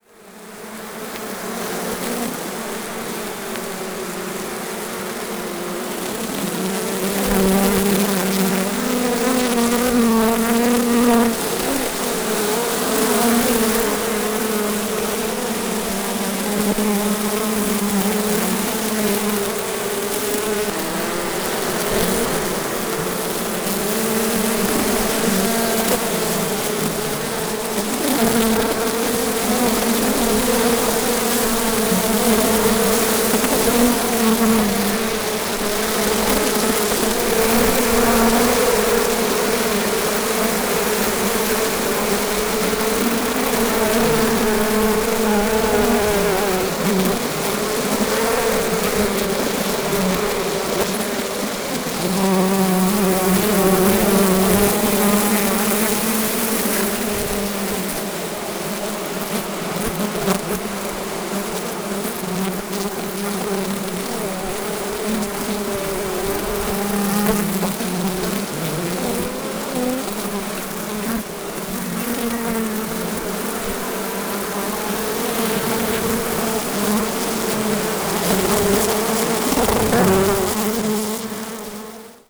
• bees recorded 12000 bees multi channel.wav
Multi-channel field recording of 12,000 Italian bees at Buckeye Creek Farm Cherokee GA.